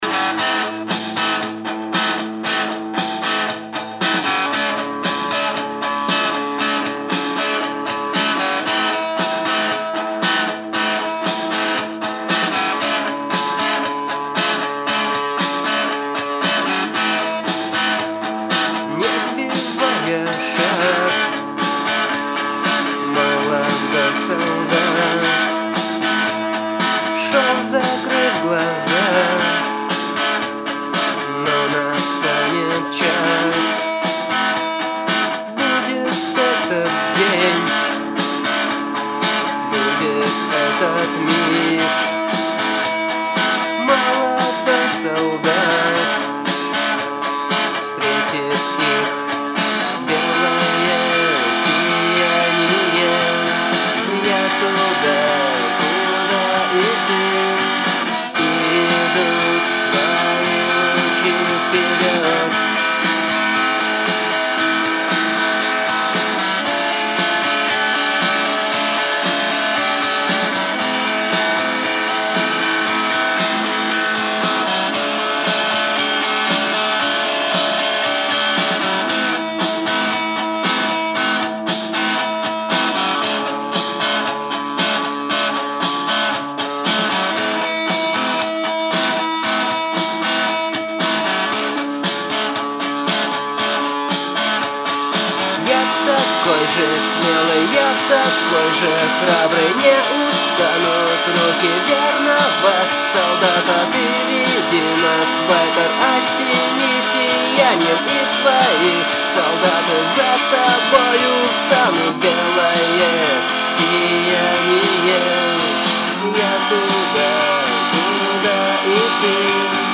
ВНИМАНИЕ! Старый ГИМН в Новом звучании!!!
Beloe_siyanieelektro.mp3